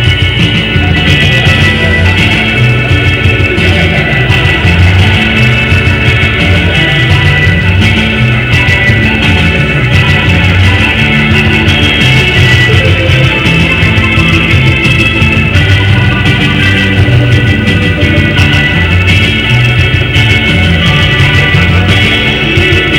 Org_Riff.WAV